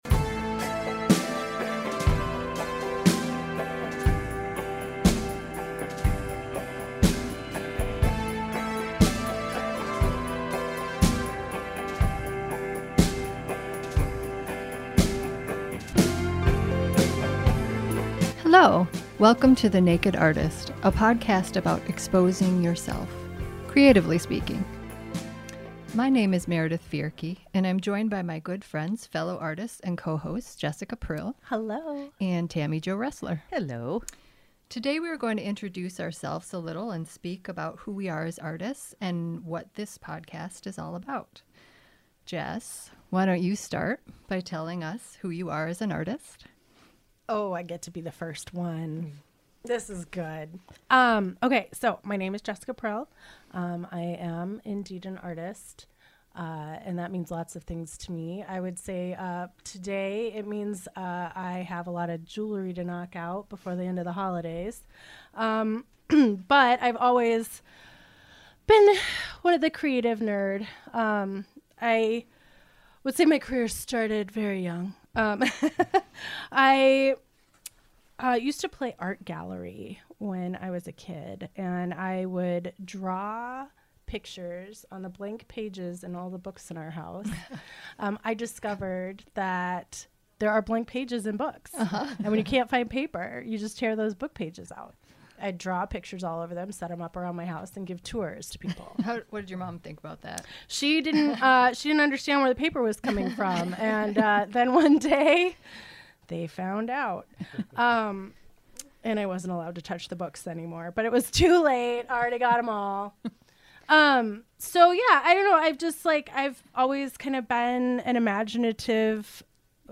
three local artists, bring you a monthly discussion about, well, pretty much anything, through an artistic and humorous lens. The Naked bit, by the way, is a metaphor.